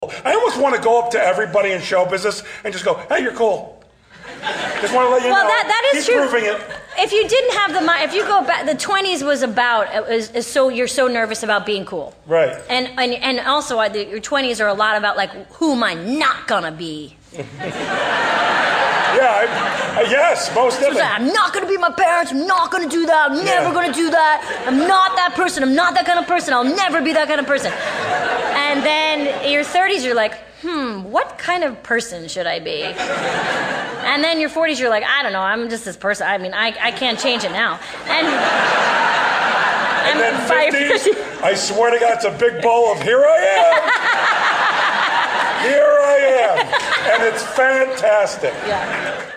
amy-poehler-and-jeff-garlin-clip.mp3